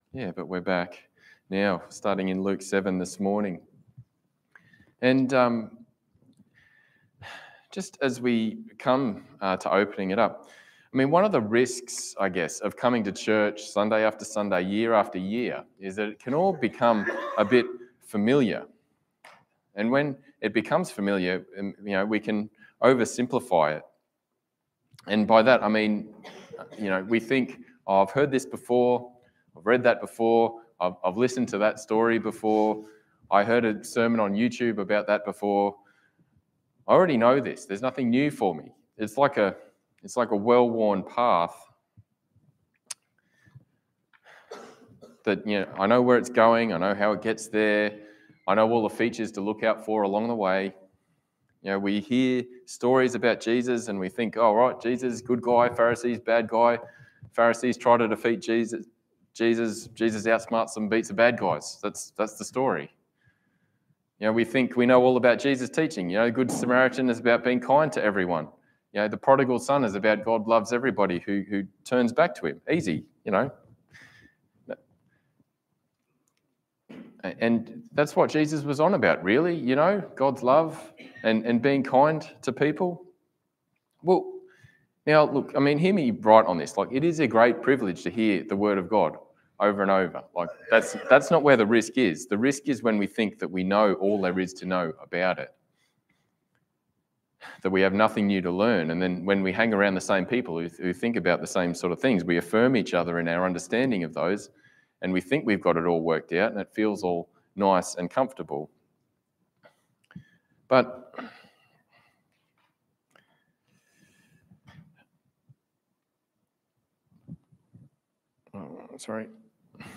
Passage: Luke 7:1-10 Service Type: Sunday Morning